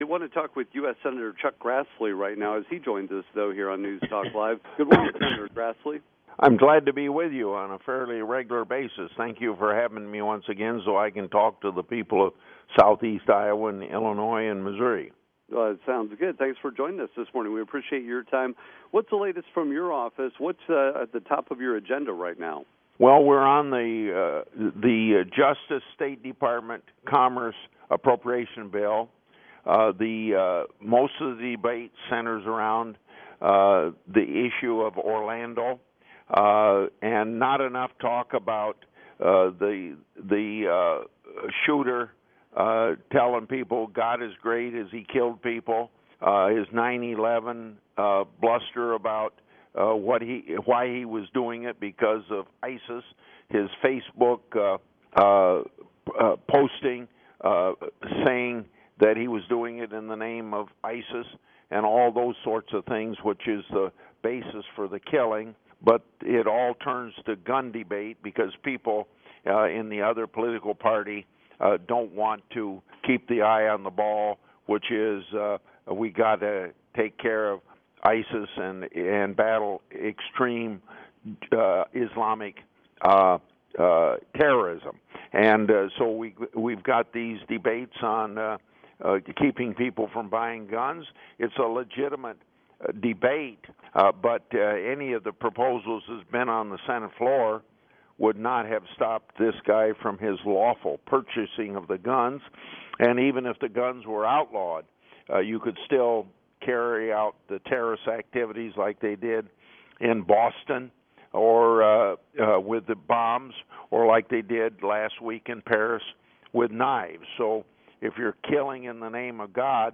Public Affairs Program, 6-22-16, WGEM.mp3